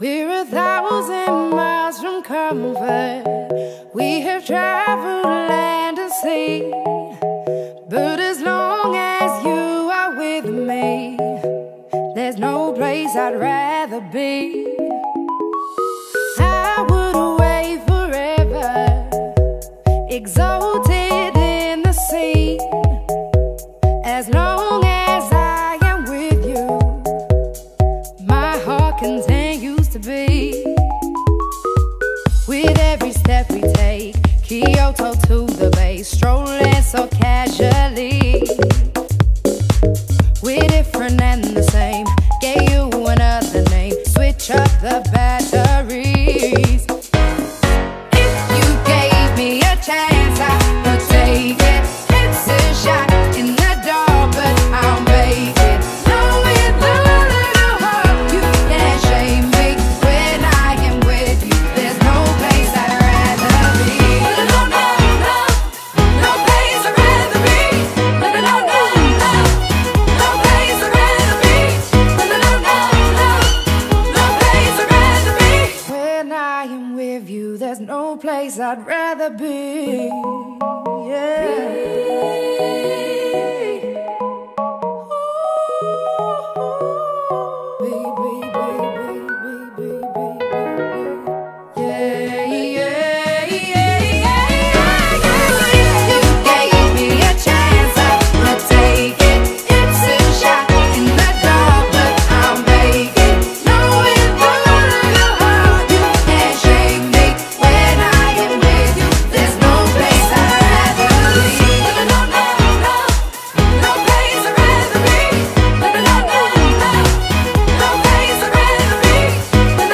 BPM121
Audio QualityMusic Cut